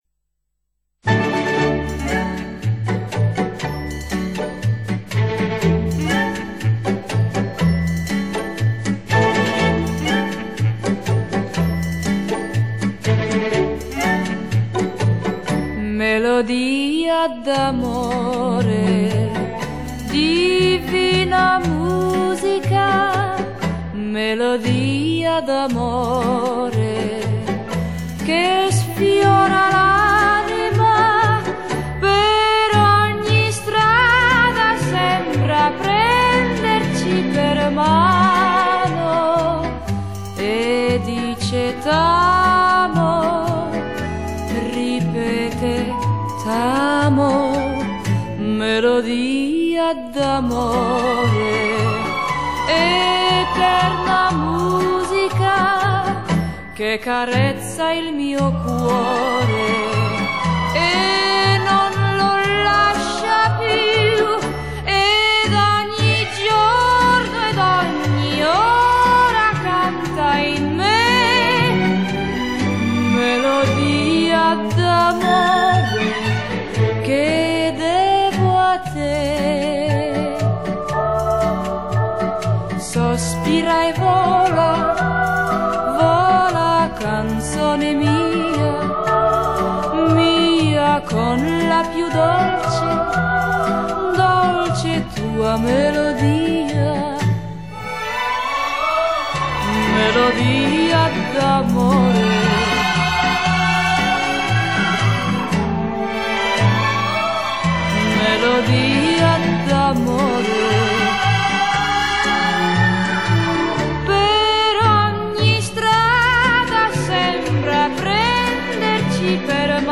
Genre: Vocal, Folk, Oldies